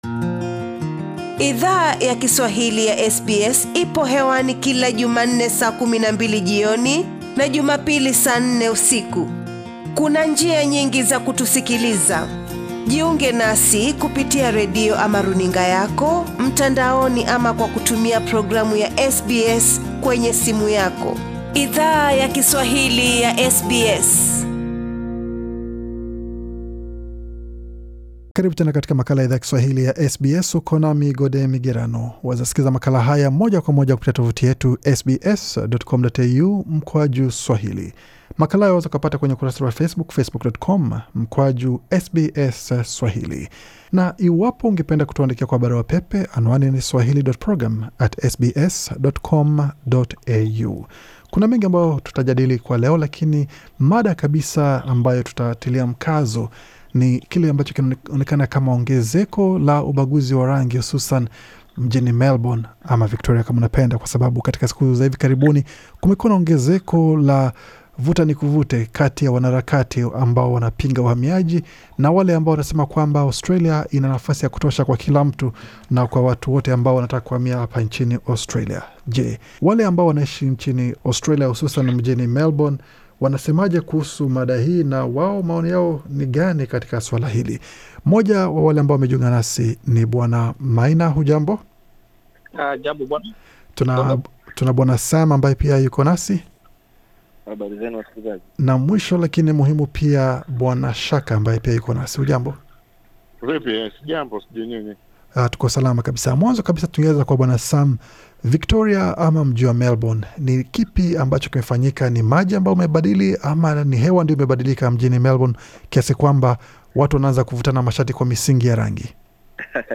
SBS Swahili ilizungumza na wanachama wa jamii yawa Kenya wanao ishi mjini Melbourne, ambao kila mmoja wao anafanya kazi katika sekta tofauti, hata hivyo wote wame kabiliana na aina toafuti ya ubaguzi wa rangi mjini humo.